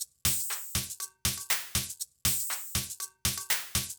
Drumloop 120bpm 09-B.wav